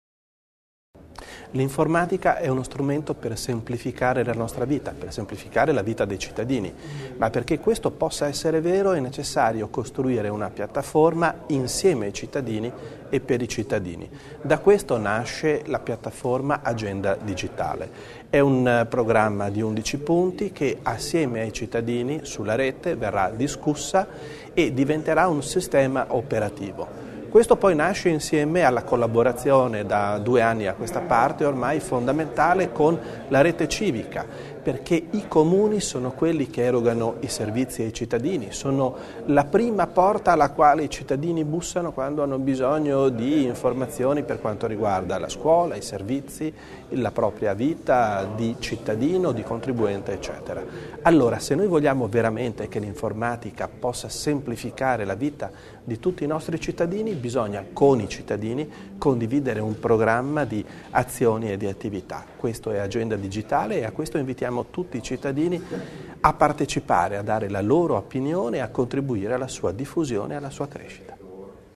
L'Assessore Bizzo spiega l'importanza dell'Agenda Digitale